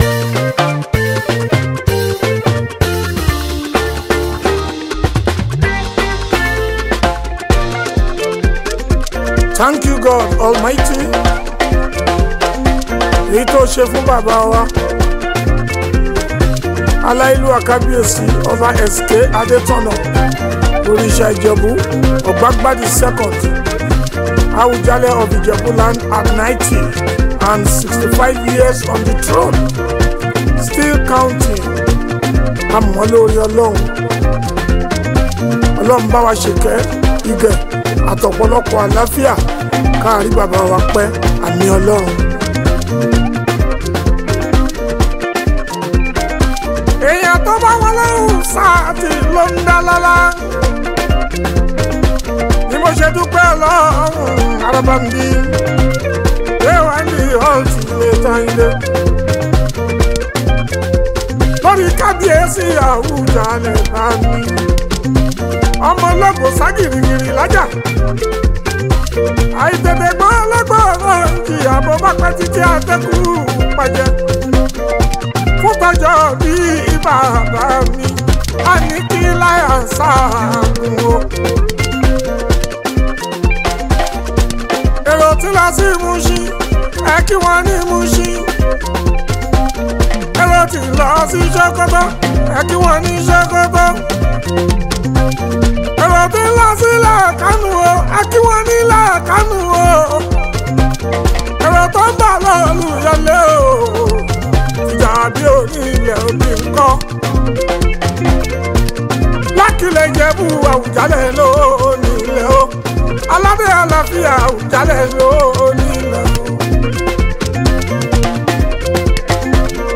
Fuji, Highlife
Nigerian Yoruba Fuji track